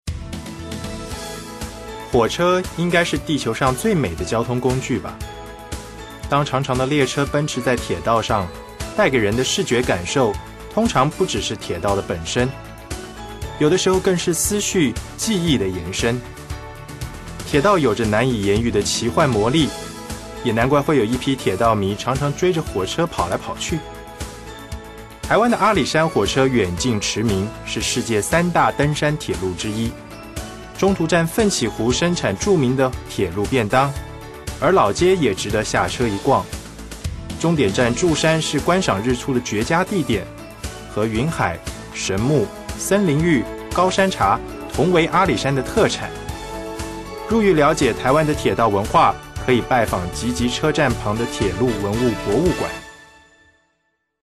Sprecher Mandarin, taiwanesisch, chinesisch
Kein Dialekt
voice over artist chinese, mandarin, taiwanese